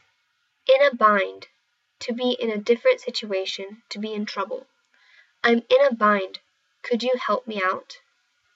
英語ネイティブによる発音は以下をクリックしてください。